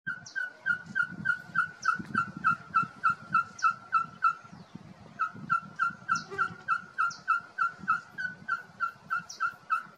Caburé Chico (Glaucidium brasilianum)
Nombre en inglés: Ferruginous Pygmy Owl
Fase de la vida: Adulto
Localización detallada: Reserva Natural Laguna de Utracán
Condición: Silvestre
Certeza: Filmada, Vocalización Grabada